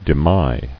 [de·my]